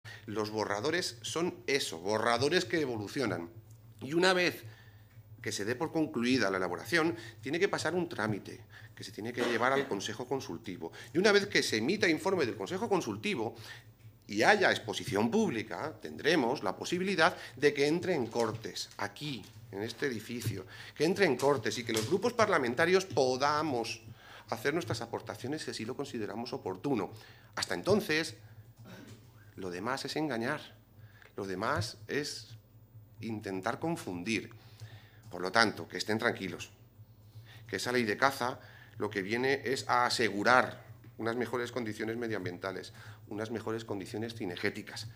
El presidente del Grupo Parlamentario Socialista, Rafael Esteban, pide a los 'populares' que no engañen ni confundan "el anteproyecto de ley está en fase de tramitación, sumando aportaciones de los distintos colectivos" algo que no hizo Cospedal con su ley de caza "hecha ad hoc para unos pocos"
Cortes de audio de la rueda de prensa